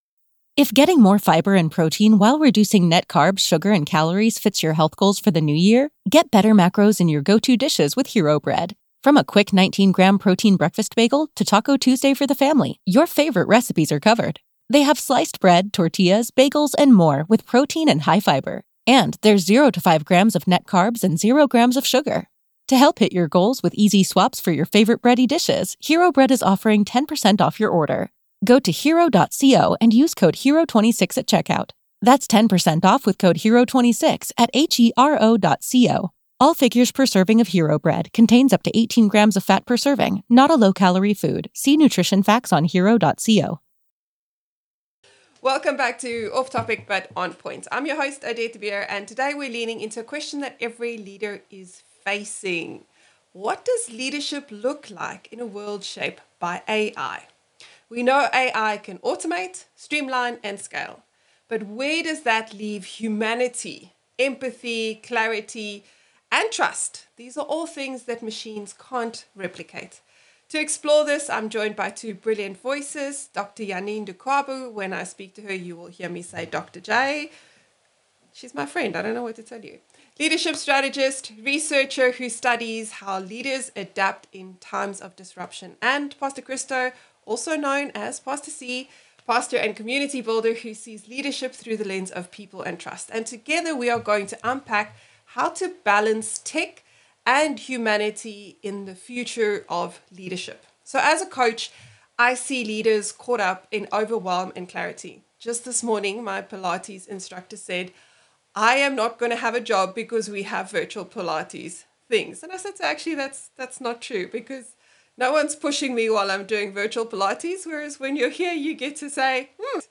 🌏 Filmed in South Australia’s Adelaide Hills—where small rooms create big impact.